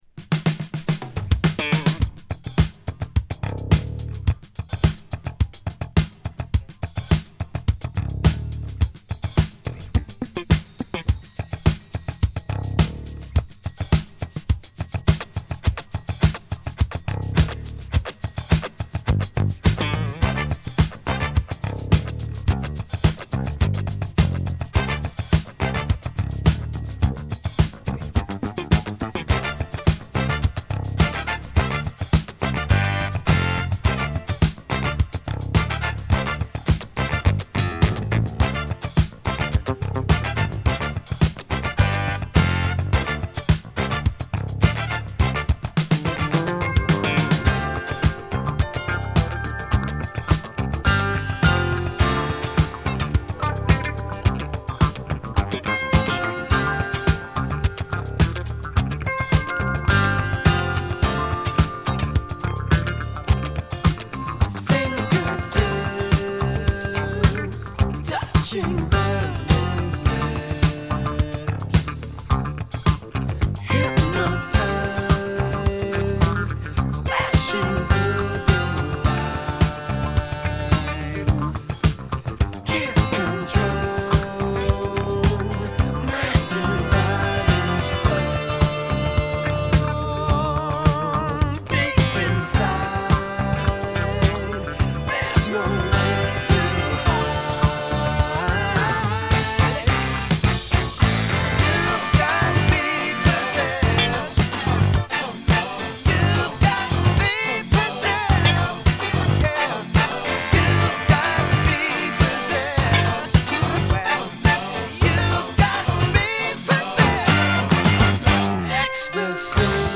ミッドテンポのアーバンブギーチューン